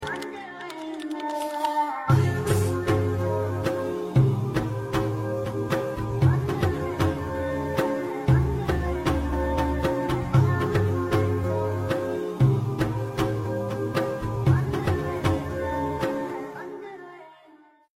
First time at Qatar Education City Mosque
giving Friday Khutbah